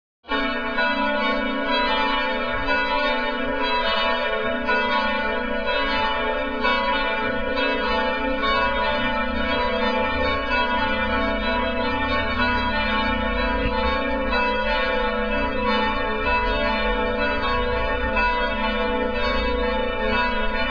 church bells.